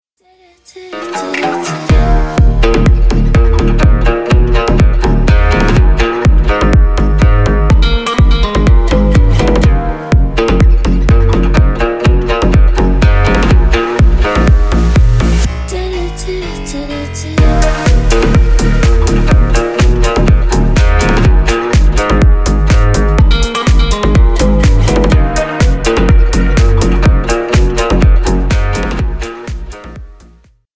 Клубные Рингтоны
Танцевальные Рингтоны